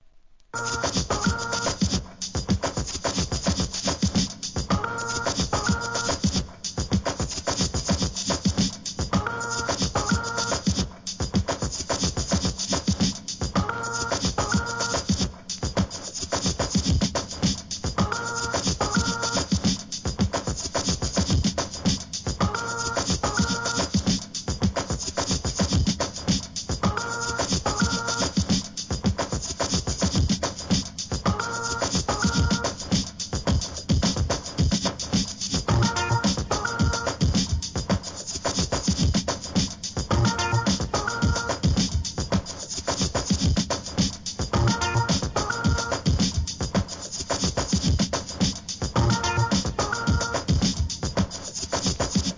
Downtempo, エレクトロニカ